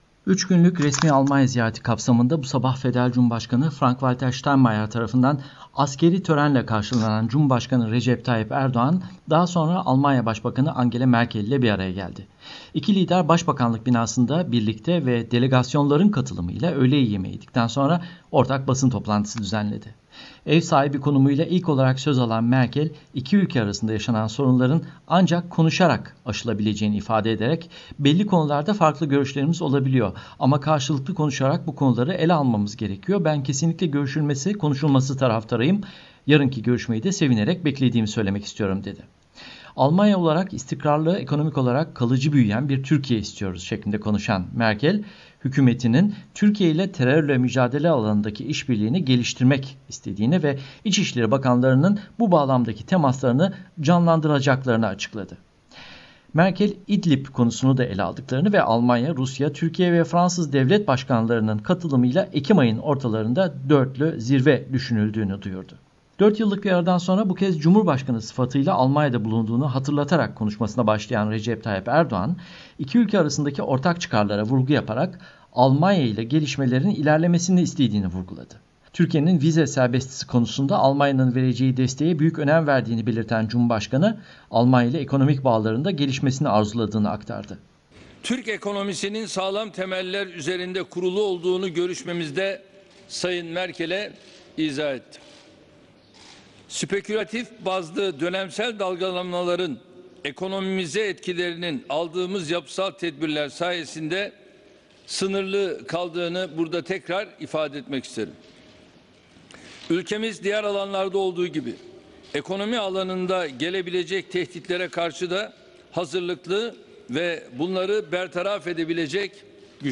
İki lider başbakanlık binasında birlikte ve delegasyonların katılımıyla öğle yemeği yedikten sonra, ortak basın toplantısı düzenledi.